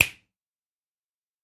На этой странице собраны различные звуки щелчков пальцами – от четких и звонких до приглушенных и мягких.
Звук щелчка большим и средним пальцем